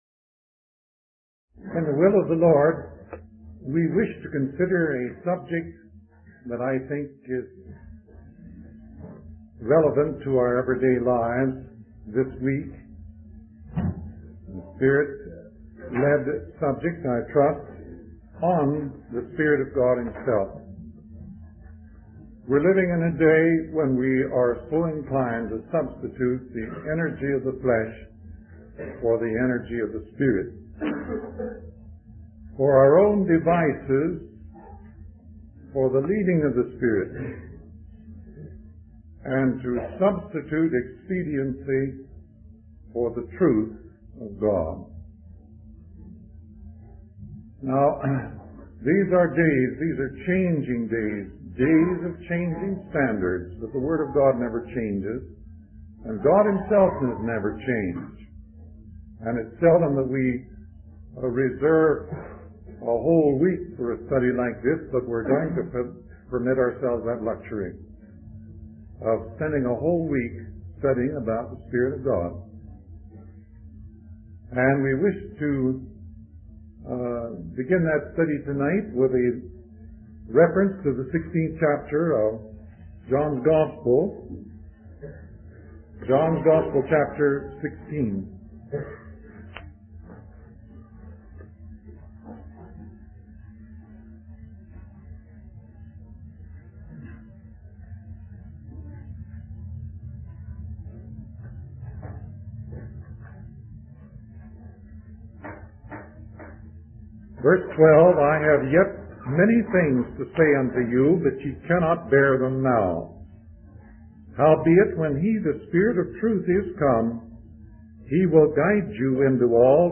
In this sermon, the speaker reflects on a series of disturbing dreams where he felt he was on the wrong side of judgment. He emphasizes the importance of relying on the leading of the Holy Spirit rather than our own devices or expediency. The speaker then turns to the 16th chapter of John's Gospel, highlighting the role of the Spirit of Truth in guiding believers into all truth and showing them things to come.